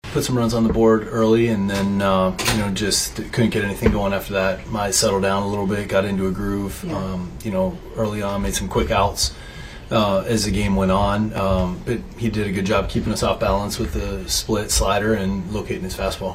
Manager Don Kelly says Tigers’ starter Casey Mize overcame a shaky start and kept the Bucs off balance.